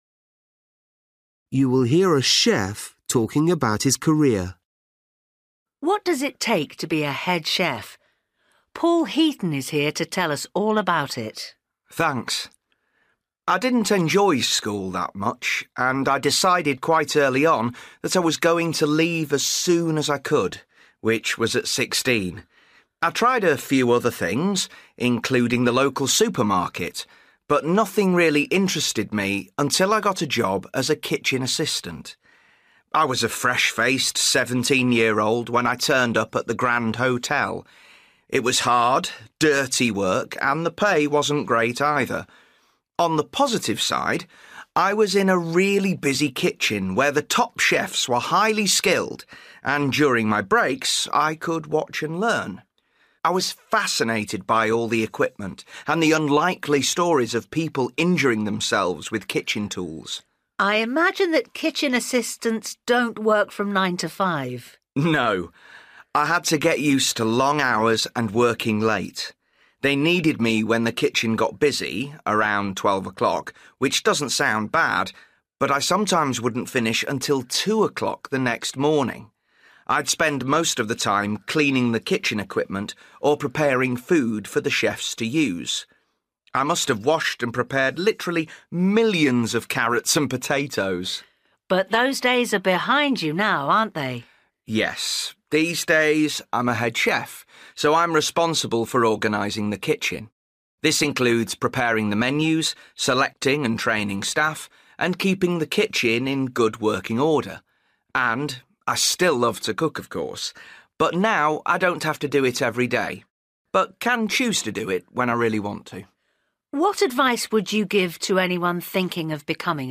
Listening: a chef talking about his career
You will hear a chef talking about his career.